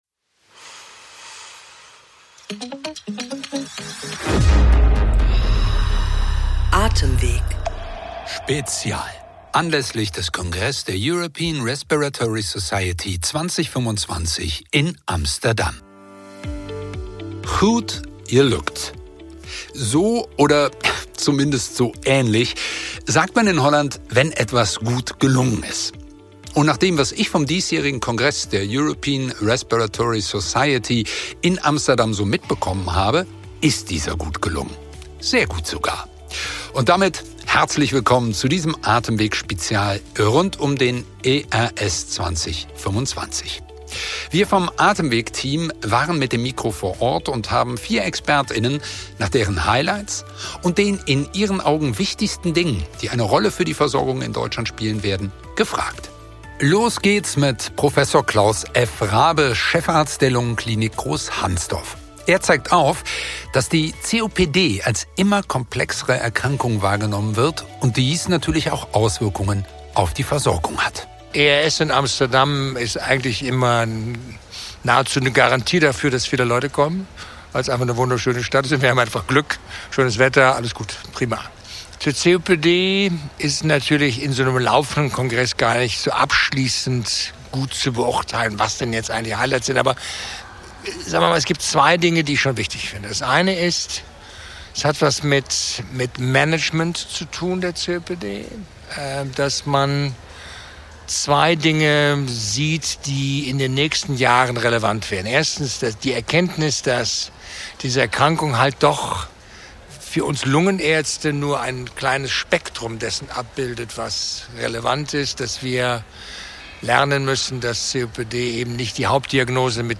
Folgen Sie uns über den ERS Congress 2025 in der neuesten Folge des ATEMWEG Podcasts, aufgezeichnet auf den Fluren der RAI Amsterdam - unsere Expert*innen Prof. Dr. med.